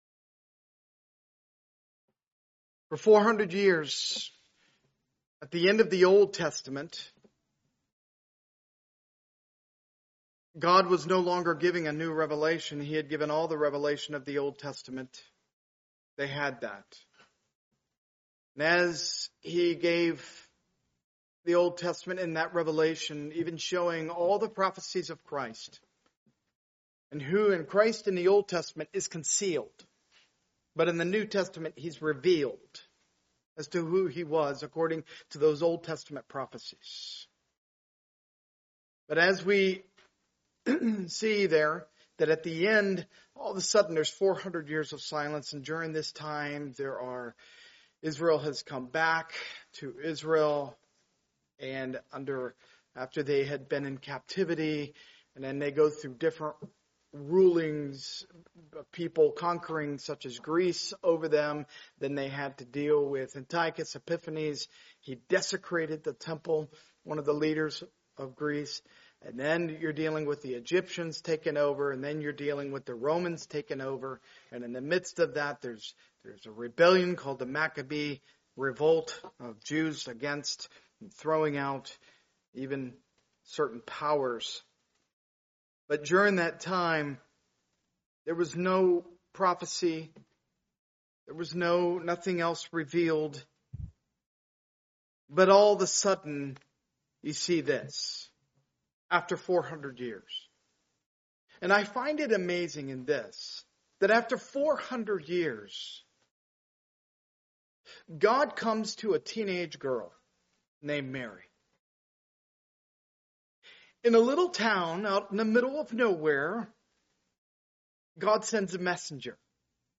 Part of the Topical series, preached at a Morning Service service.